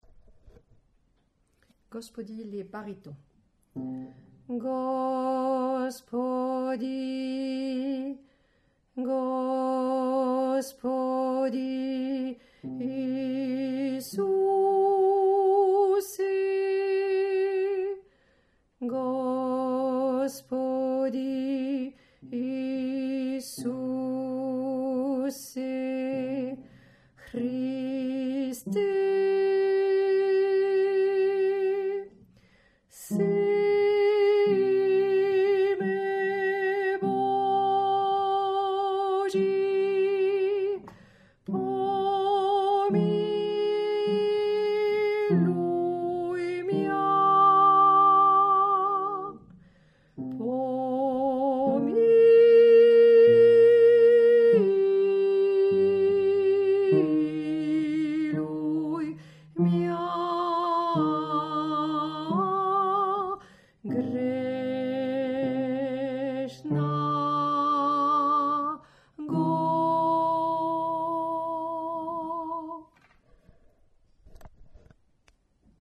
gospodi_Baryton.mp3